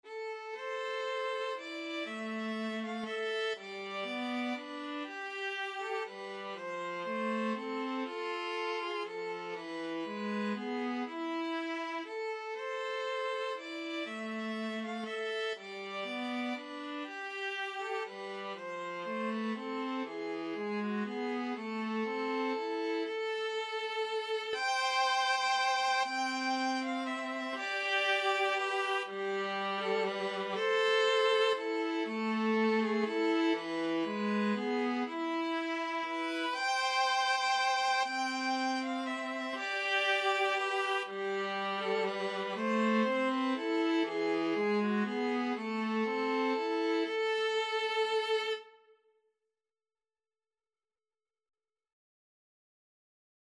Free Sheet music for Violin-Viola Duet
A minor (Sounding Pitch) (View more A minor Music for Violin-Viola Duet )
3/4 (View more 3/4 Music)
Traditional (View more Traditional Violin-Viola Duet Music)